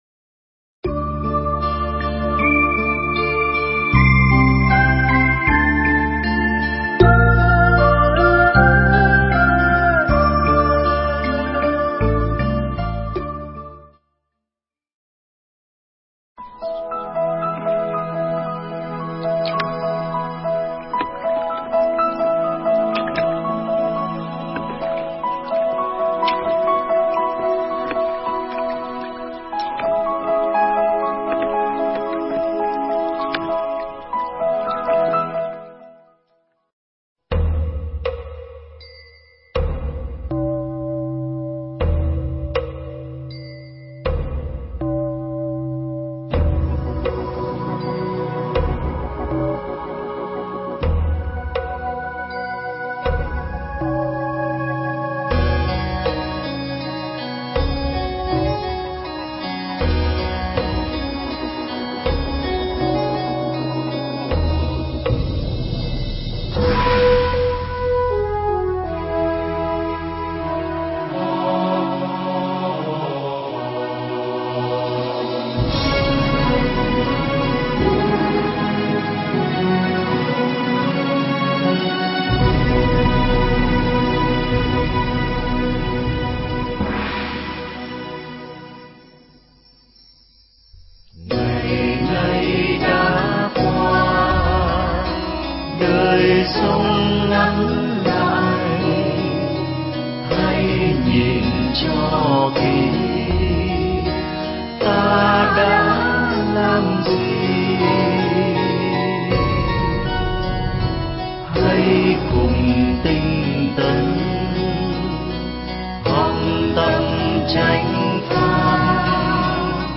Nghe Mp3 thuyết pháp Lễ Tạ Ơn Thầy 2009
Mp3 pháp thoại Lễ Tạ Ơn Thầy 2009